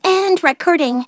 synthetic-wakewords
ovos-tts-plugin-deepponies_Trixie_en.wav